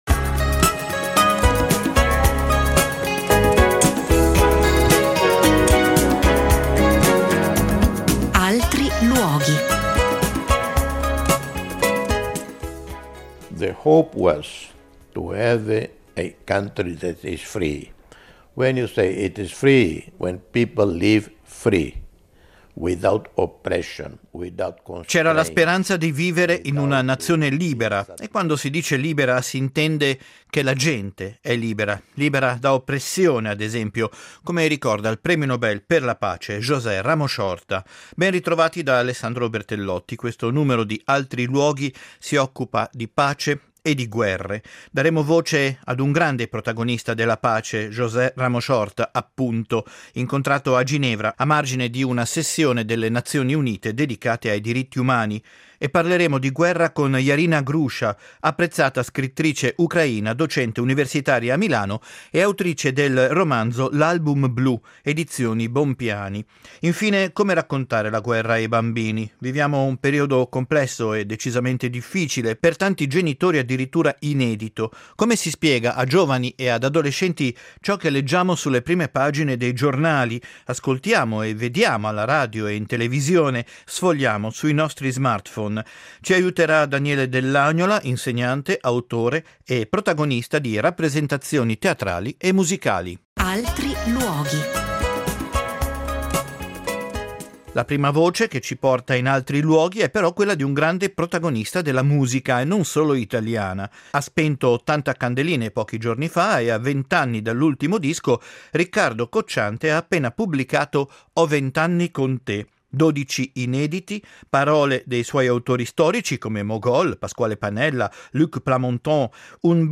Nel corso del programma saranno proposte alcune tracce inedite, accompagnate da una intervista all’artista italo-francese, che non dimentica temi come la guerra e le incomprensioni che caratterizzano la nostra esistenza.
Infine una cartolina sonora porterà il pubblico verso altriluoghi.